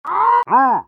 pyro_paincrticialdeath01.mp3